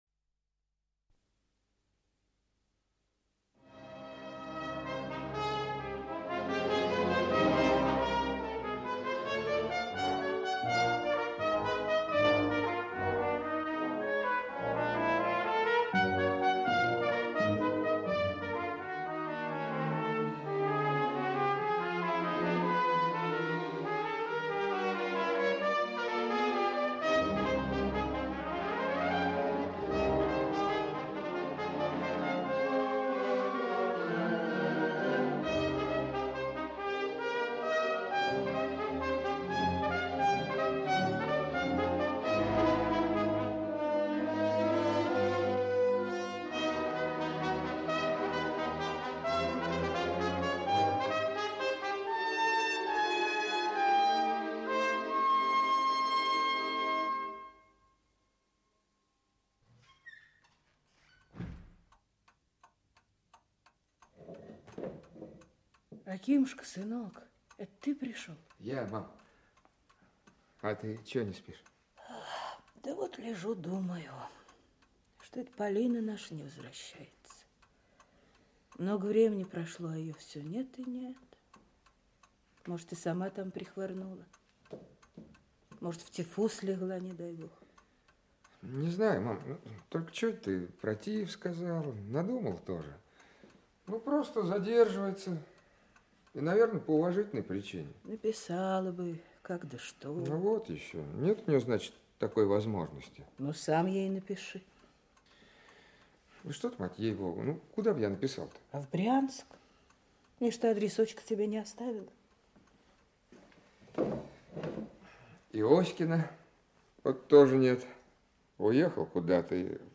Аудиокнига Железный перстень. Глава 4 | Библиотека аудиокниг
Глава 4 Автор Зиновий Исаакович Фазин Читает аудиокнигу Актерский коллектив.